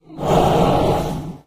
rogue_bloodsucker_invis.ogg